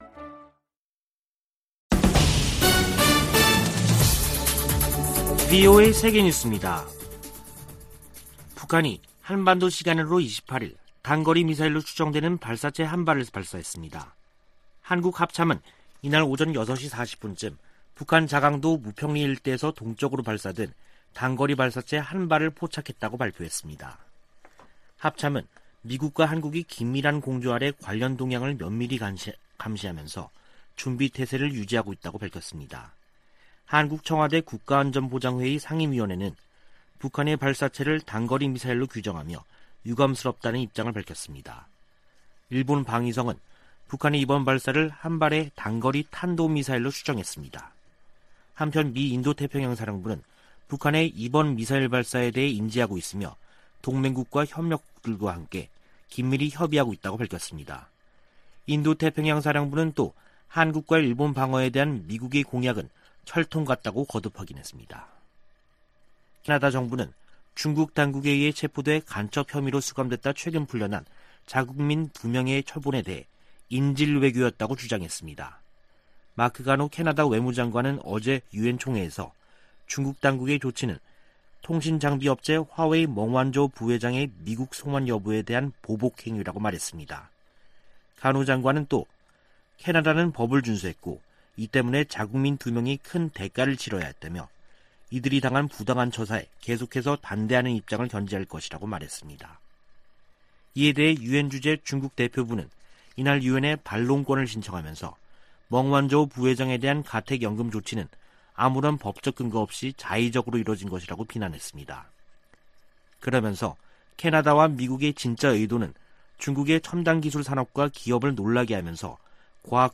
VOA 한국어 간판 뉴스 프로그램 '뉴스 투데이', 2021년 9월 28일 3부 방송입니다. 북한은 김여정 노동당 부부장이 대남 유화 담화를 내놓은 지 사흘 만에 단거리 미사일 추정 발사체를 동해 쪽으로 발사했습니다. 미 국무부는 북한의 발사체 발사를 규탄했습니다. 유엔주재 북한 대사가 미국에 대북 적대시 정책을 철회하라고 요구했습니다.